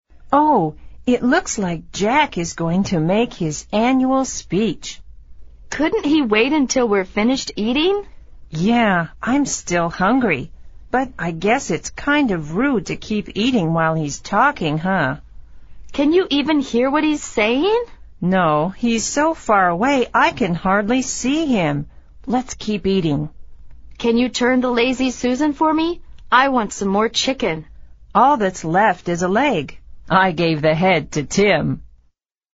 美语会话实录第88期(MP3+文本):Lazy Susan